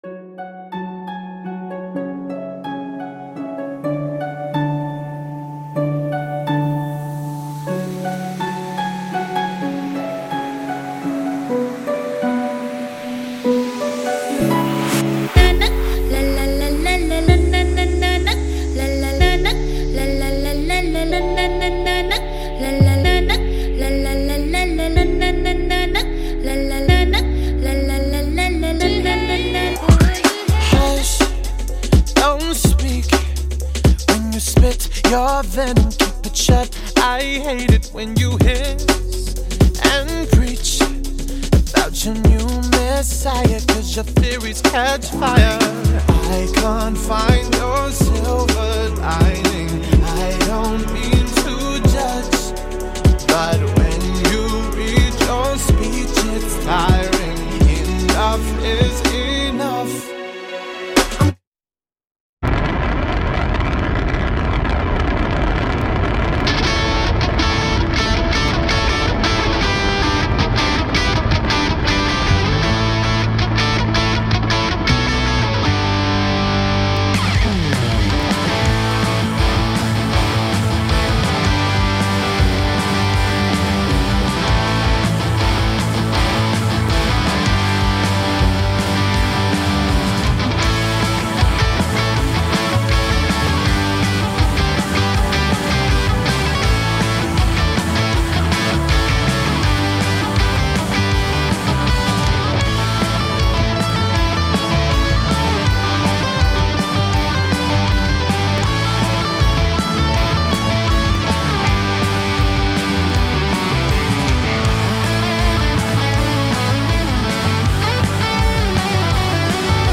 Programa con la mejor musica rock